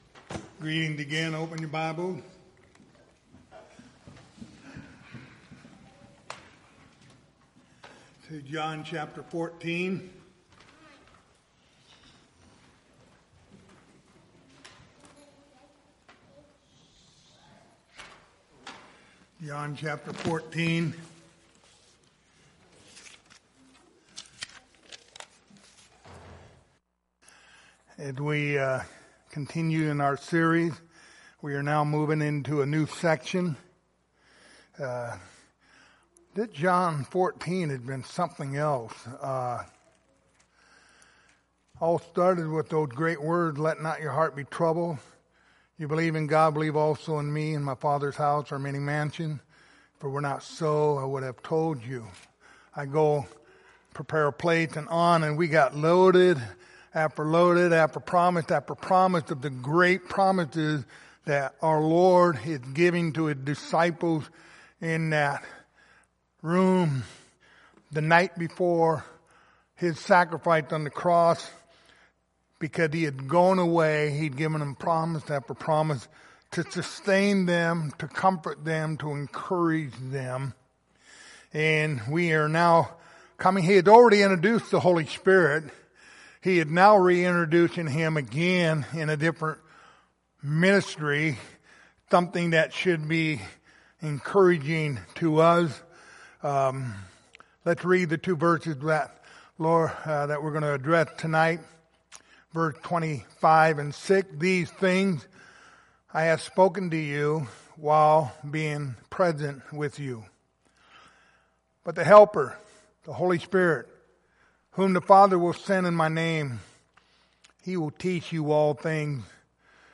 Passage: John 14:25-26 Service Type: Wednesday Evening Topics